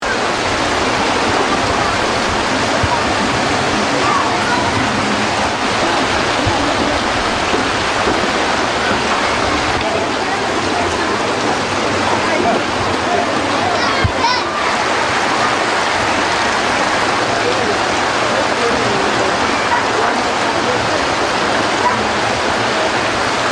Rain atmospheres 2